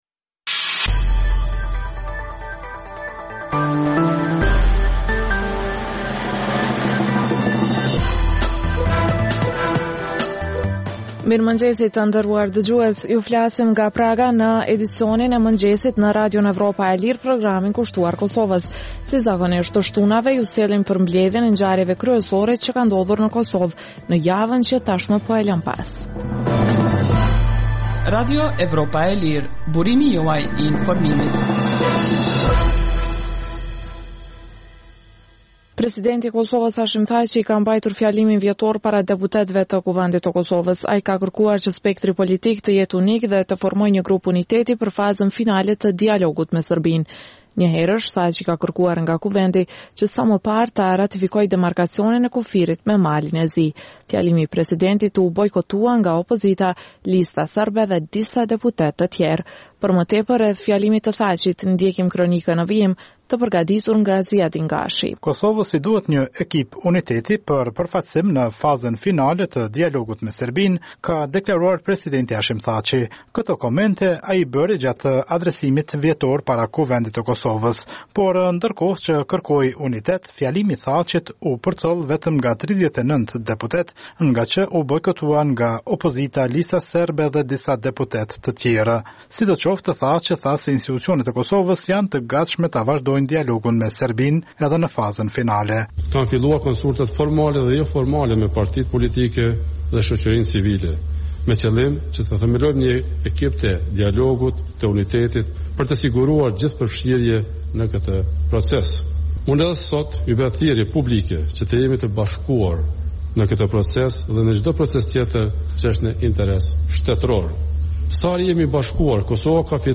Emisioni i mëngjesit rëndom fillon me buletinin e lajmeve nga Kosova, rajoni dhe bota.